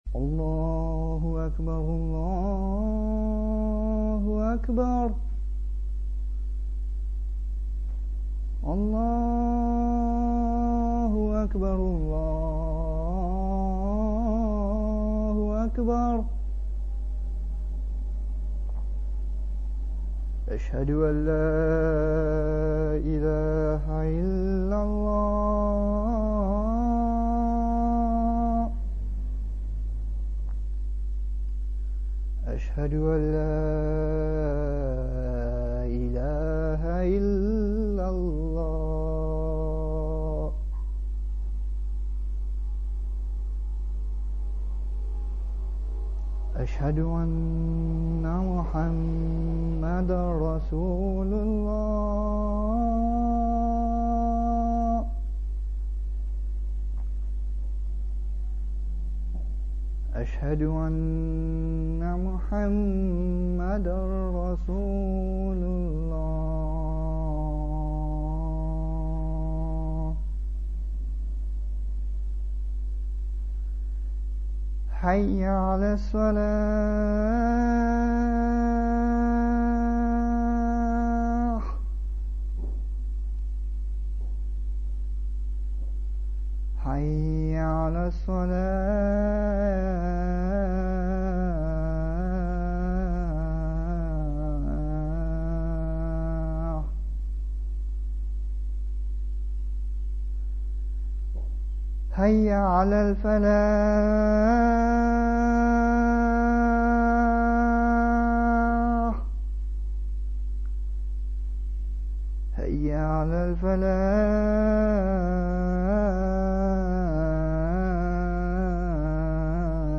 ADHAAN - CALL TO PRAYER
When the time for any of the 5 obligatory prayers comes, a man (called a mu-adh-dhin) and calls aloud these words to summon muslims in the neighbourhood of the mosque to come to prayer: